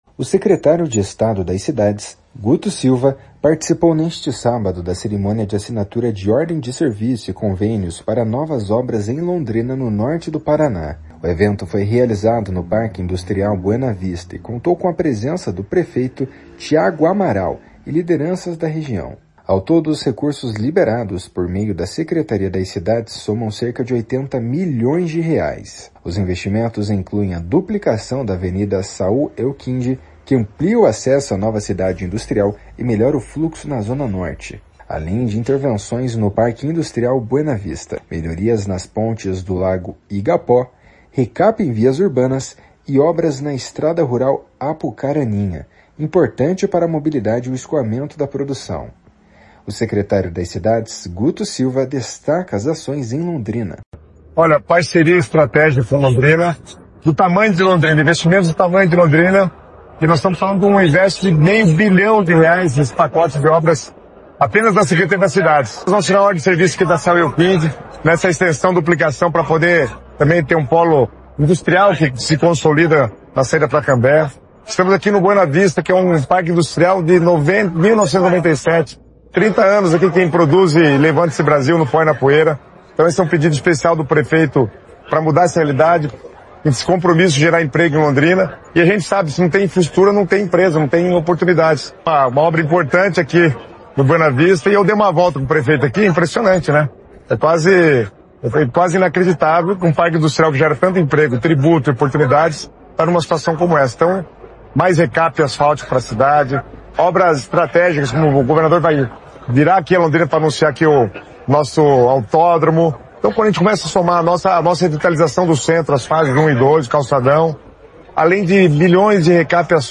O secretário das Cidades, Guto Silva, destaca as ações em Londrina. // SONORA GUTO SILVA //